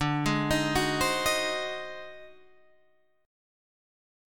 D 7th Flat 9th Flat 5th